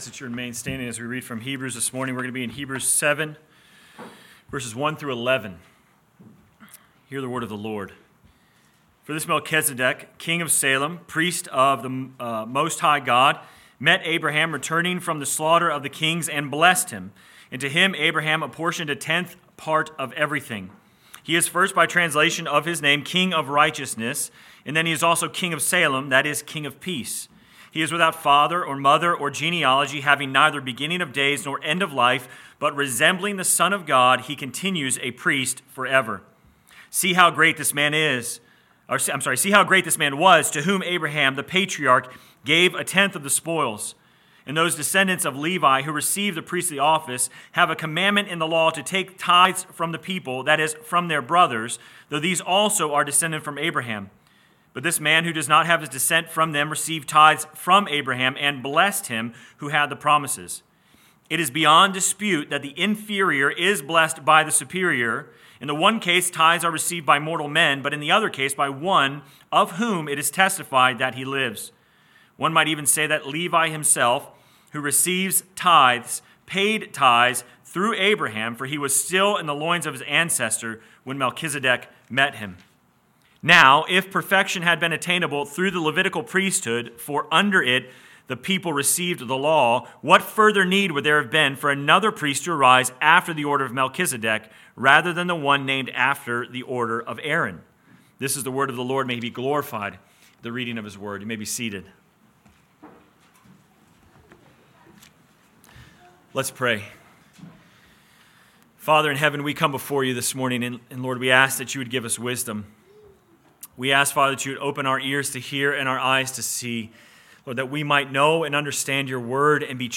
Sermon Text: Hebrews 7:1-11 First Reading: Genesis 14:17-24 Second Reading: Romans 3:9-20